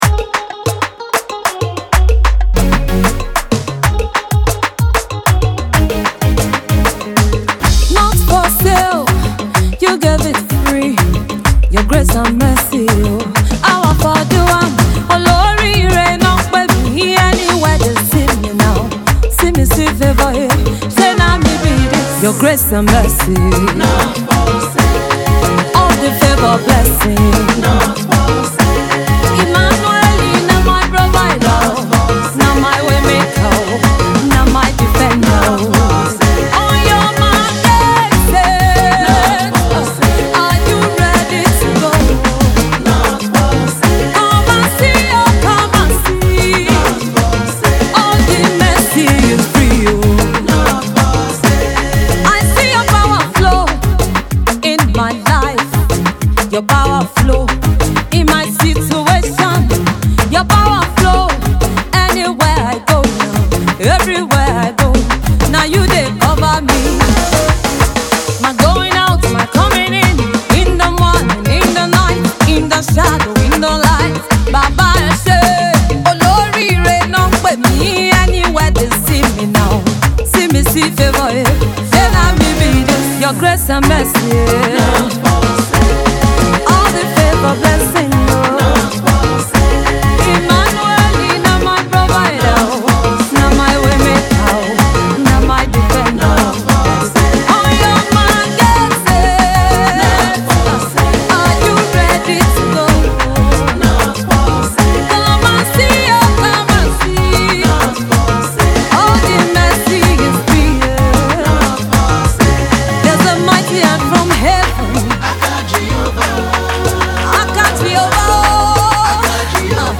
an upbeat song